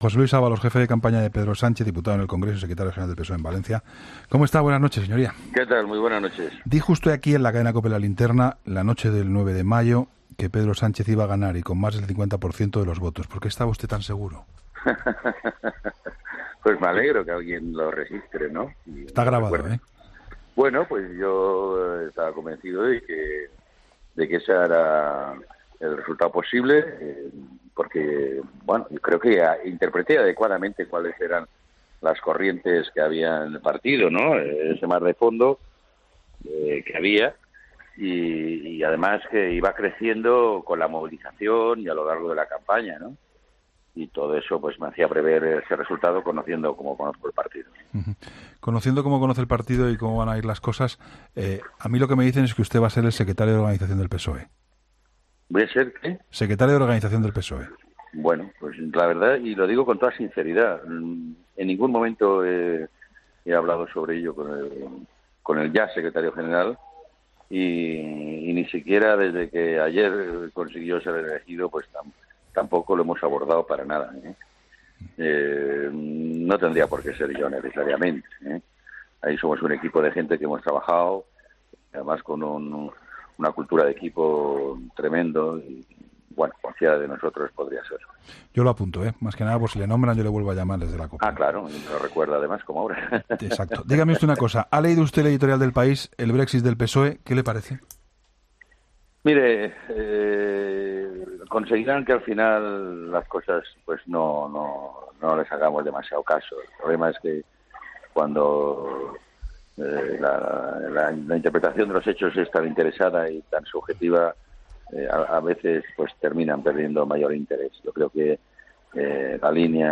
Entrevista a José Luis Ábalos